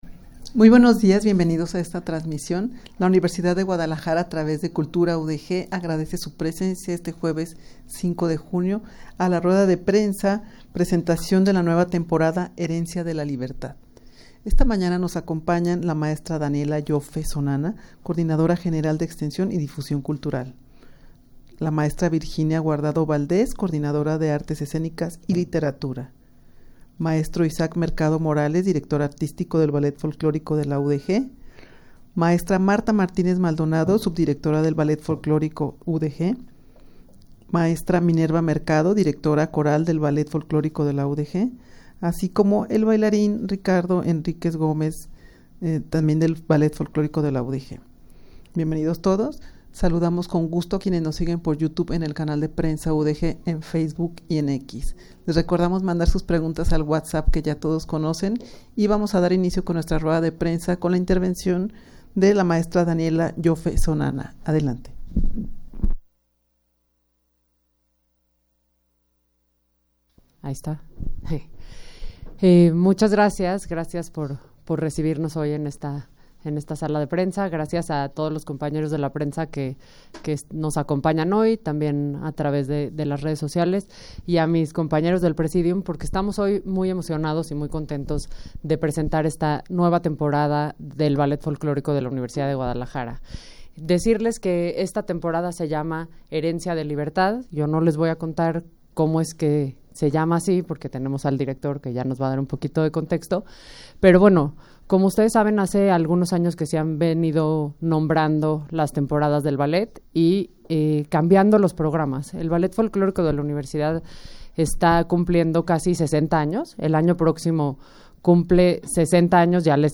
Audio de la Rueda de Prensa
rueda-de-prensa-presentacion-herencia-de-libertad-nueva-temporada-del-ballet-folclorico-de-la-udeg.mp3